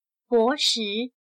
博识/Bóshì/Erudito, educado, instruido.